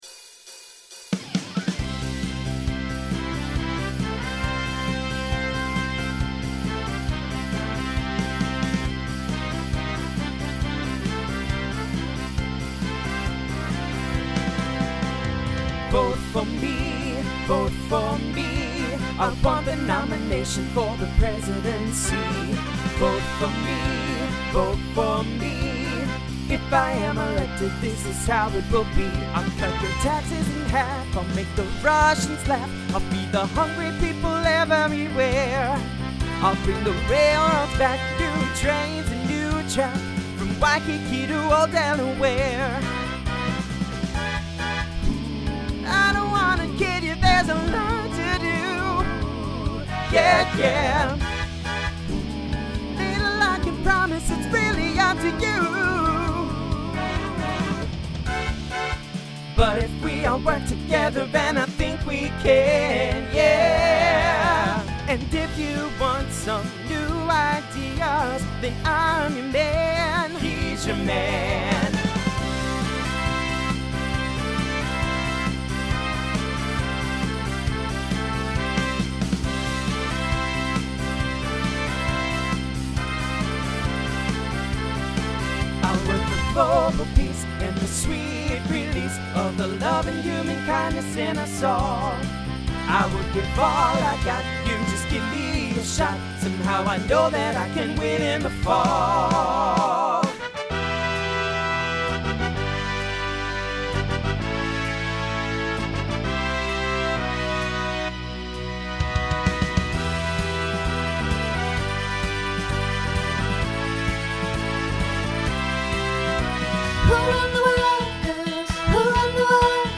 TTB/SSA/SATB
Genre Pop/Dance , Rock Instrumental combo
Transition Voicing Mixed